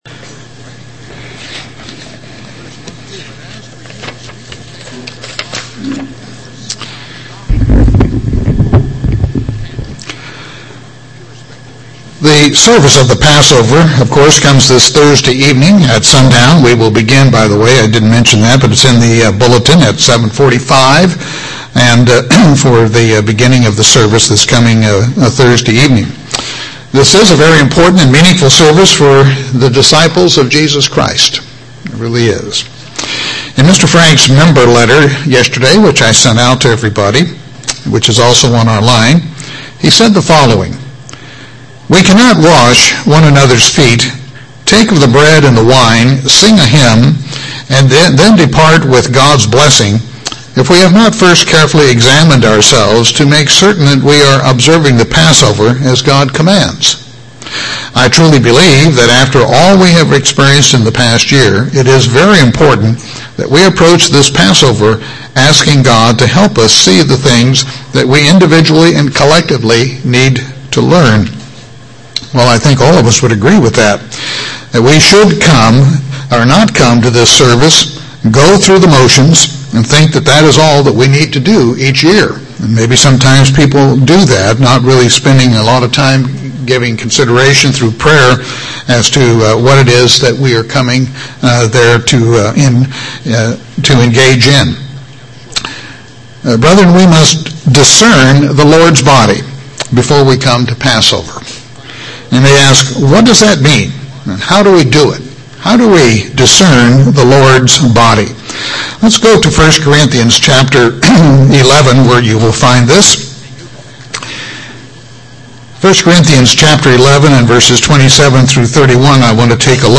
3-31-12 Sermon.mp3